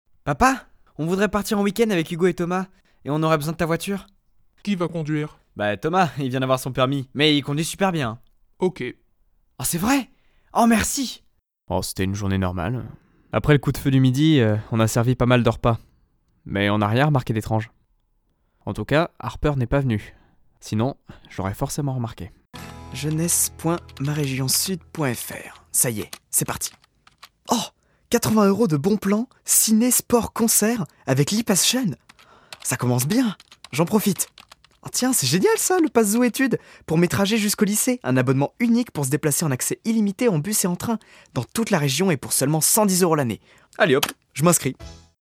Bandes-son
Voix off